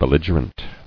[bel·lig·er·ent]